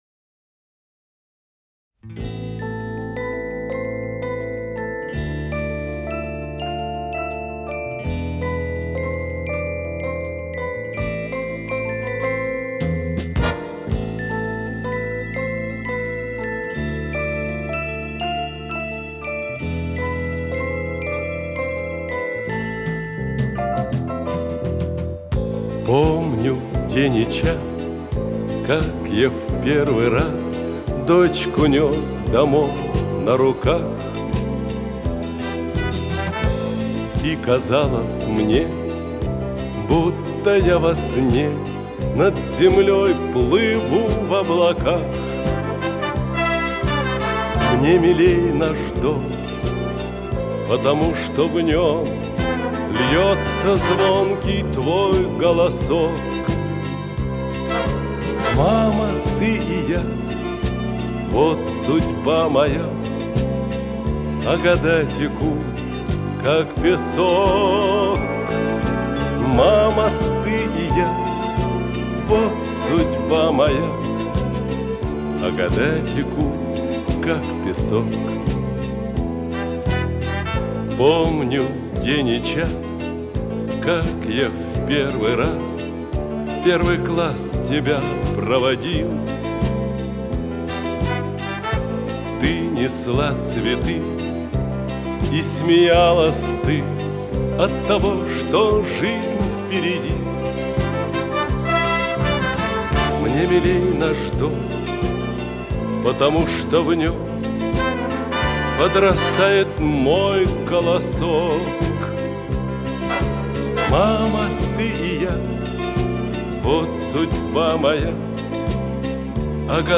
с профессиональной магнитной ленты
ВариантДубль стерео